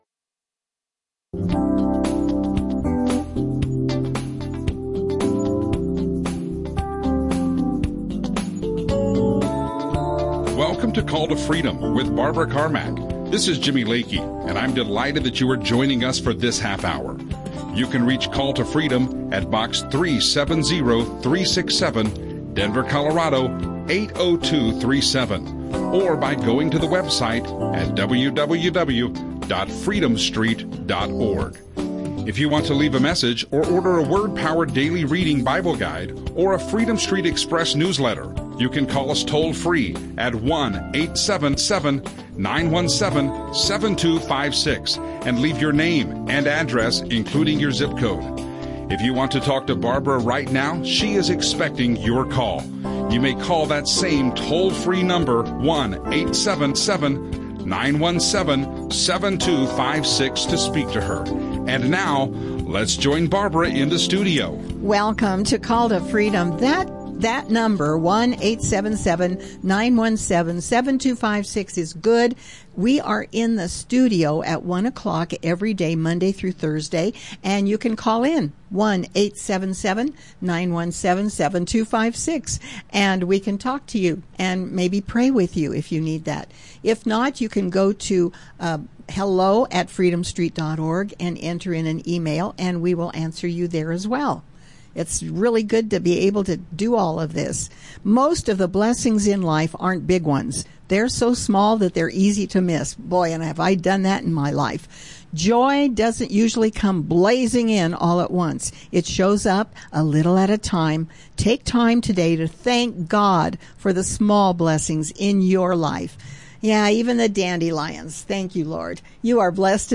Christian radio